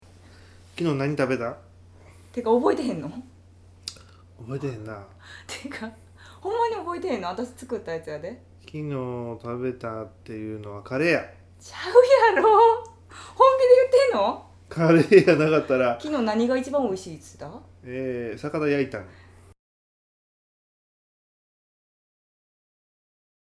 male female couple casual